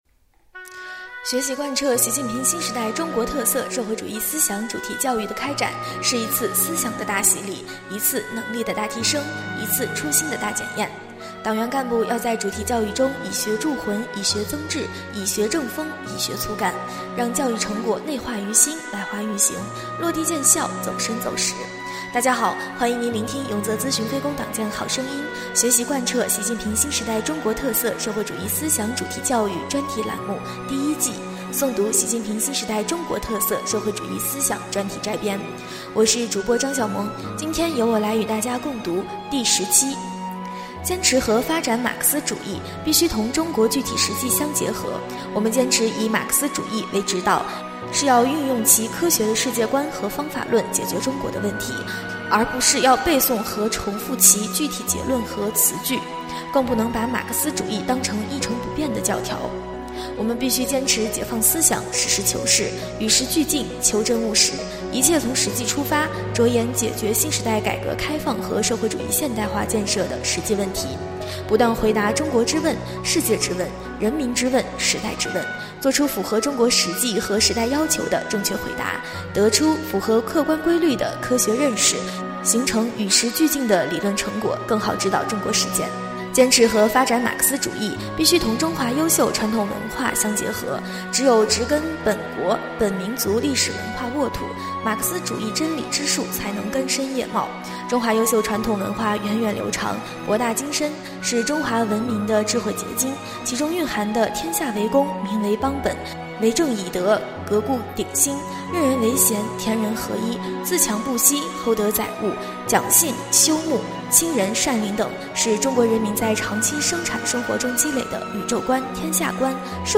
【诵读】《习近平新时代中国特色社会主义思想专题摘编》第10期-永泽党建